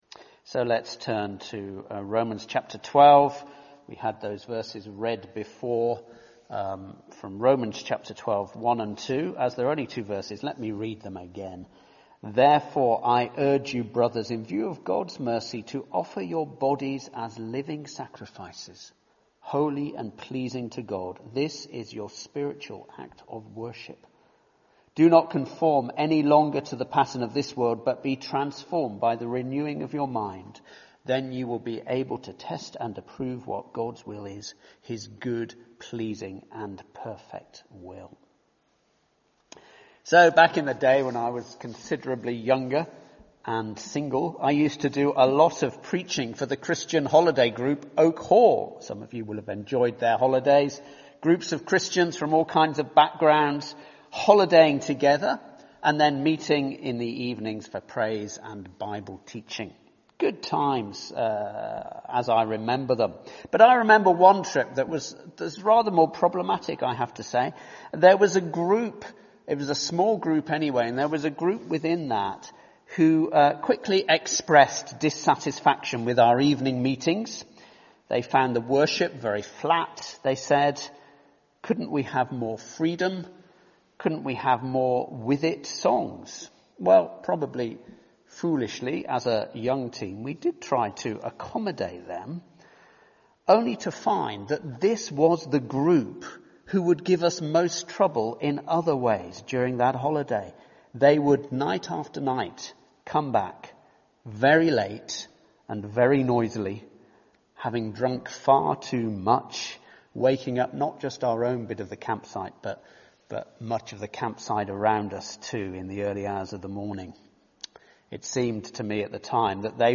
speaks on Living Sacrifices from Romans 12:1-2.